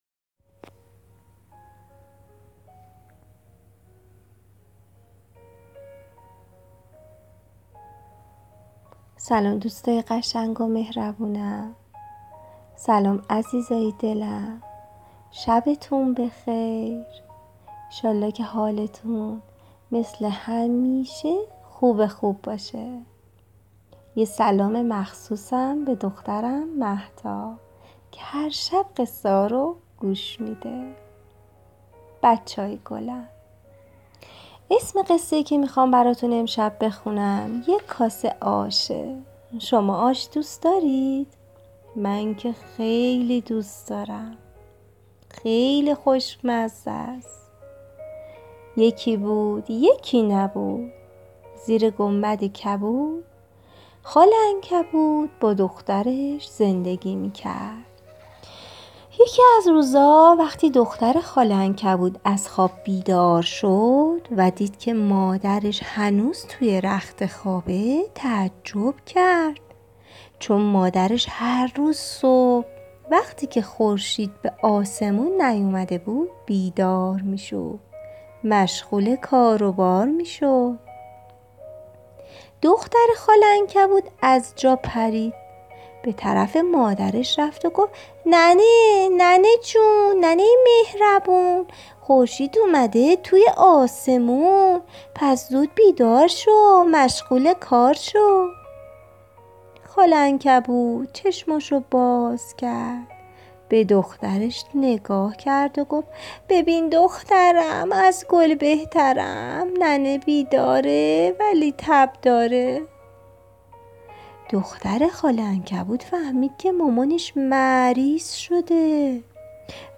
قصه صوتی کودکان دیدگاه شما 3,228 بازدید